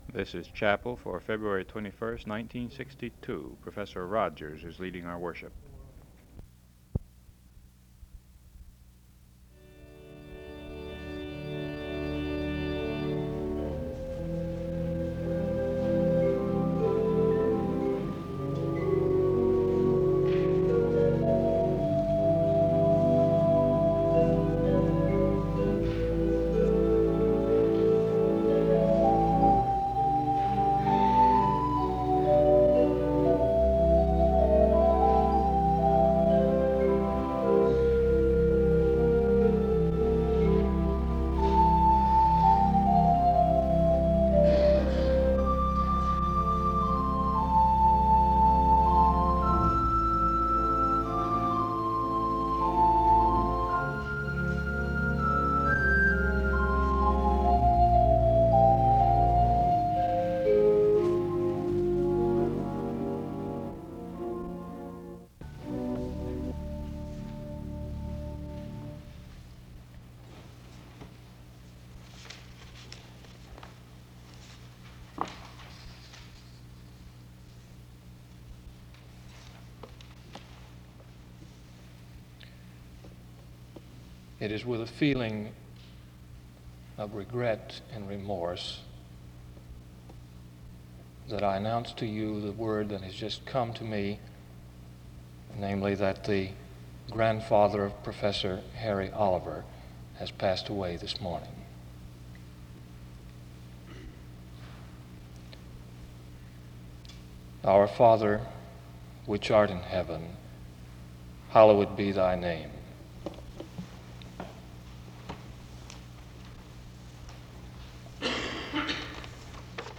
The service begins with instrumental music from 0:00-1:09.
A prayer is offered from 2:02-2:24.
A responsive reading takes place from 7:04-7:54. A closing prayer is offered from 8:15-8:47. Music plays from 9:00-12:31.